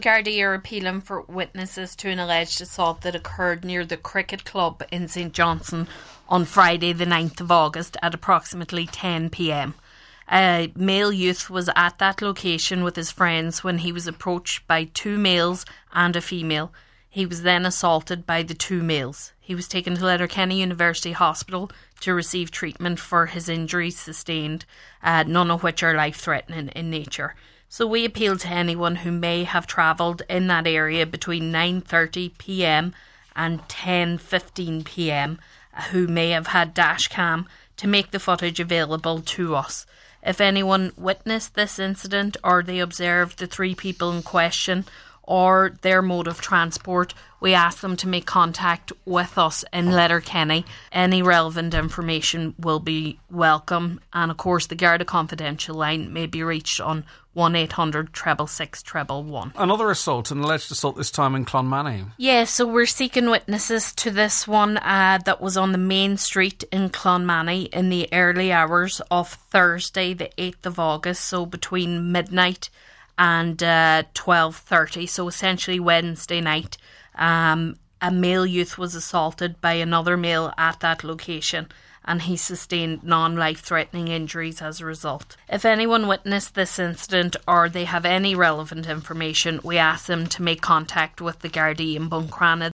Community Garda Information segment on today’s Nine til Noon Show